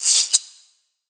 Perc 3.wav